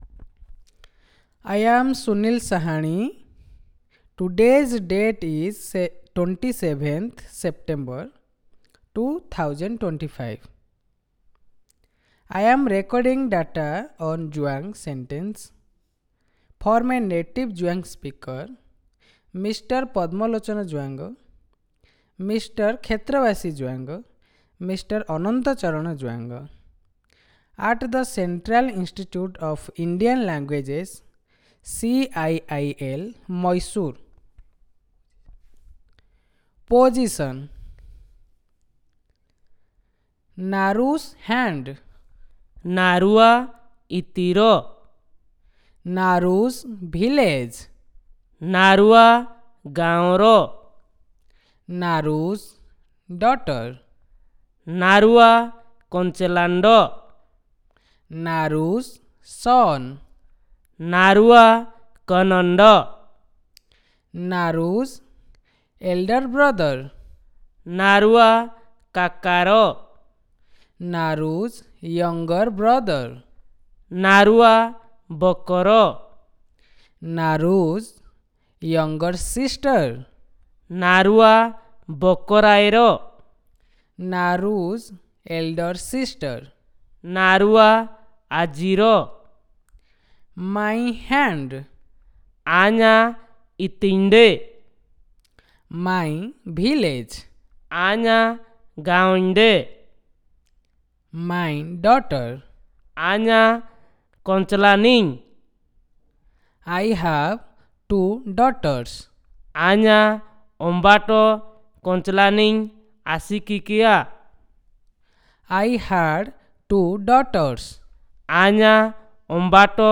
Elicitation of possession